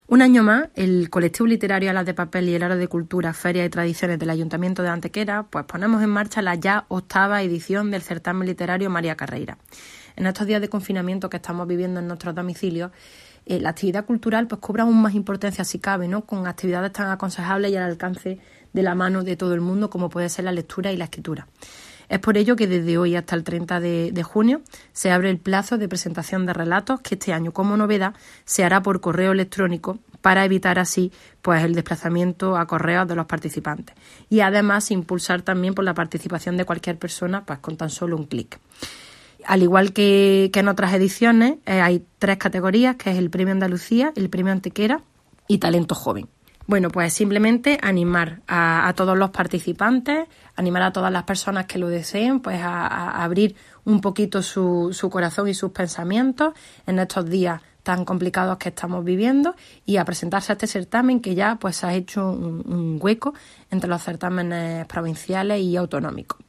Cortes de voz
Audio de la teniente de alcalde Elena Melero sobre la puesta en marcha del certamen   454.62 kb  Formato:  mp3